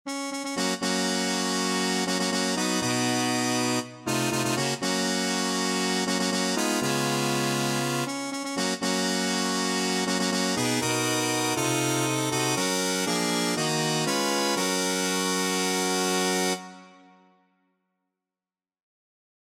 Key written in: G♭ Major
How many parts: 4
Type: Barbershop
All Parts mix: